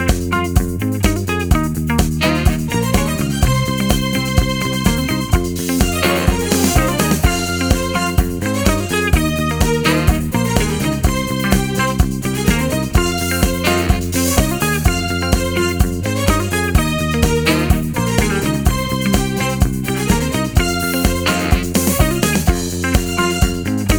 no Backing Vocals or Vox Percussion Disco 3:26 Buy £1.50